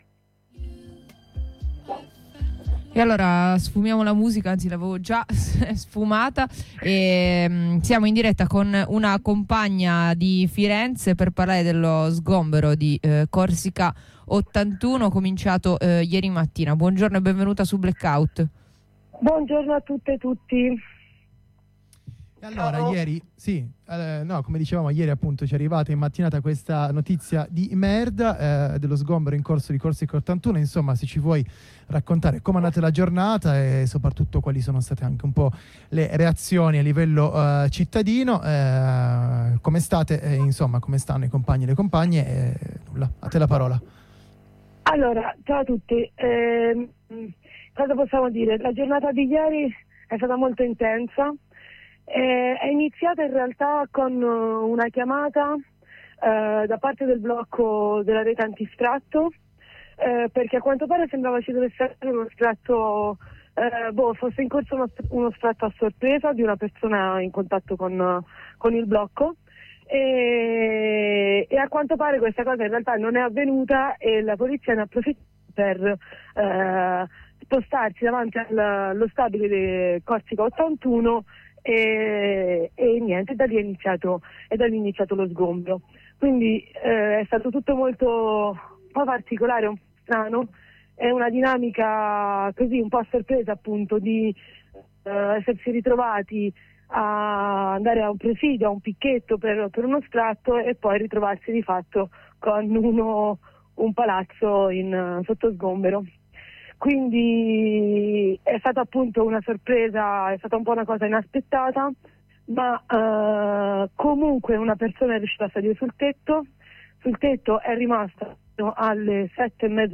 Dalla resistenza sul tetto fino al corteo per il quartiere, ci facciamo raccontare da una compagna come è andata la giornata di ieri e quali altre iniziative sono in programma, con un corteo per sabato di cui seguiranno aggiornamenti.